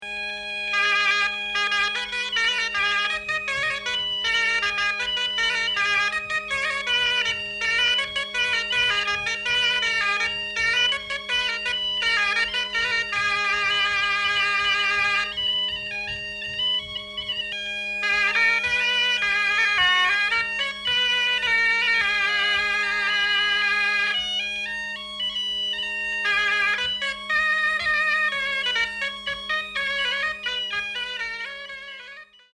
Origine : Bretagne (pays de Loudéac) Année de l'arrangement : 2020
Suite instrumentale
Indication : ronde, baleu
bombarde, biniou